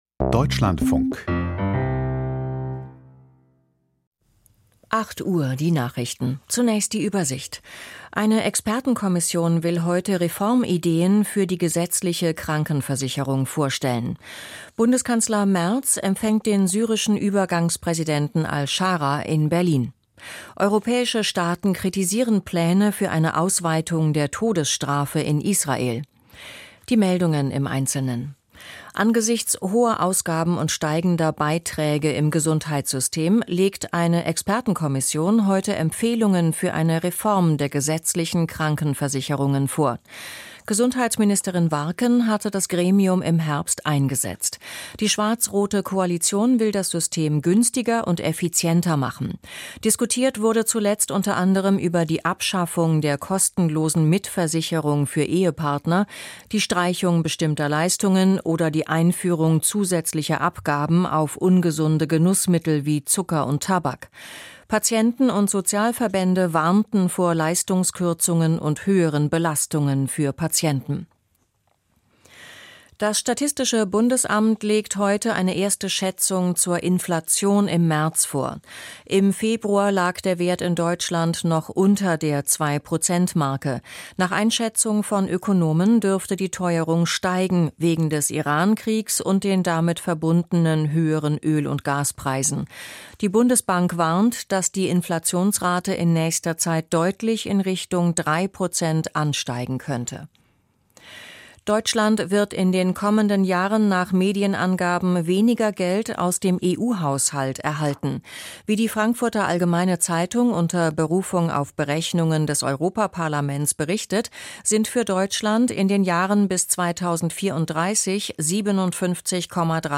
Die Nachrichten vom 30.03.2026, 08:00 Uhr